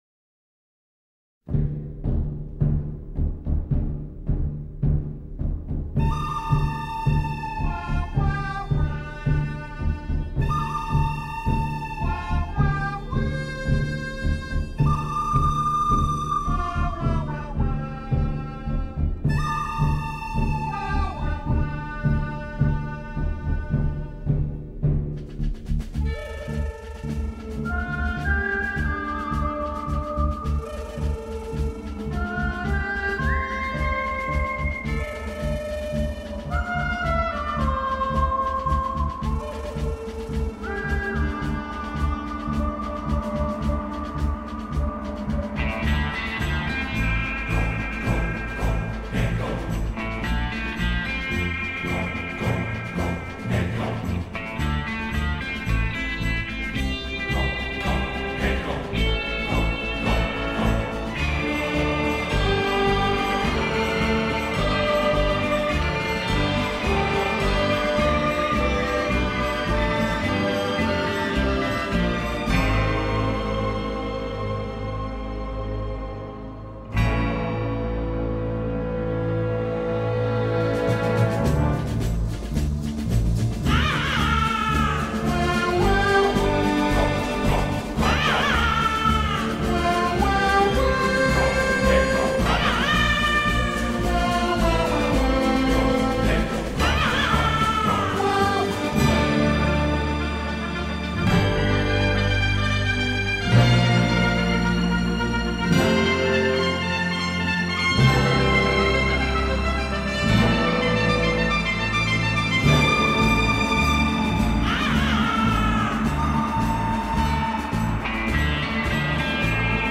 musica